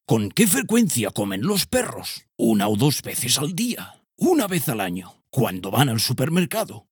TEST HISTORIA PERRO-Narrador-10.ogg